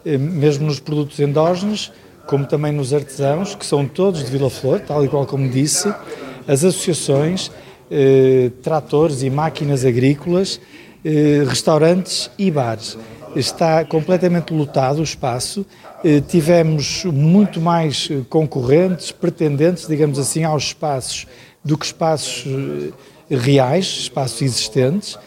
O presidente da Câmara, Pedro Lima, realça que o espaço ficou lotado com 200 inscrições: